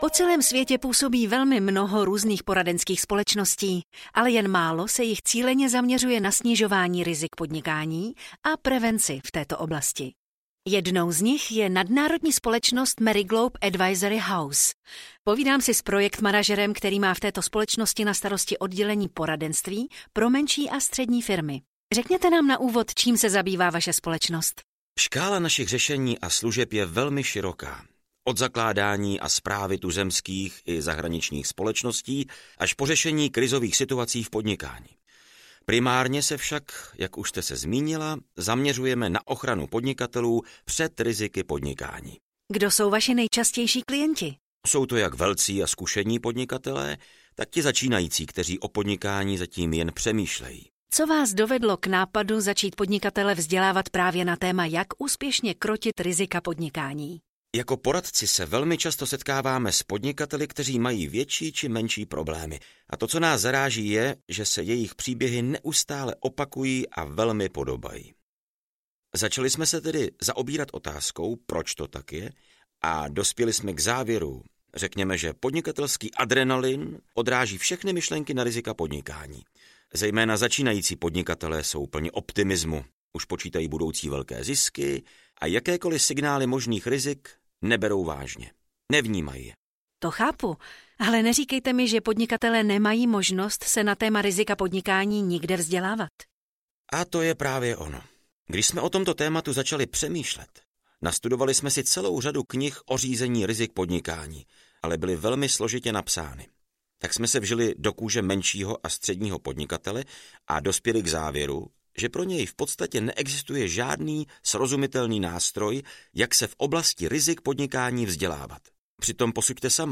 Desatero dobrého podnikatele audiokniha
Ukázka z knihy